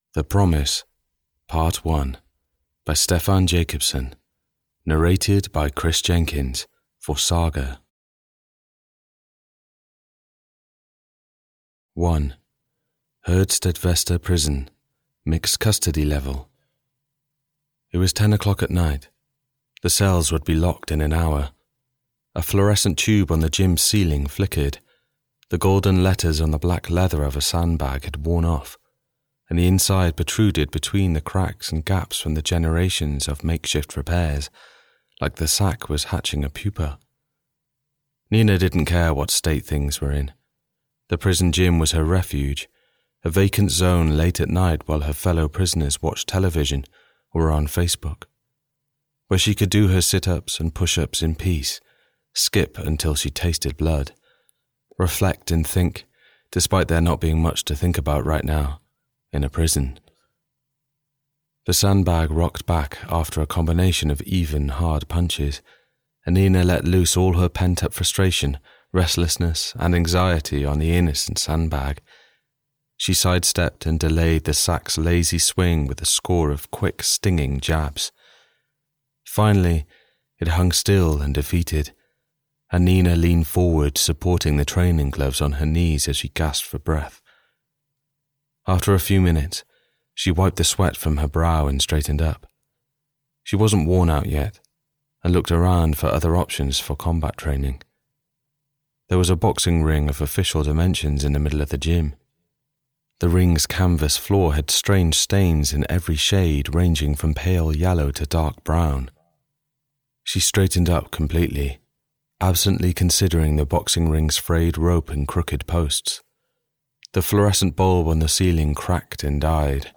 The Promise - Part 1 (EN) audiokniha
Ukázka z knihy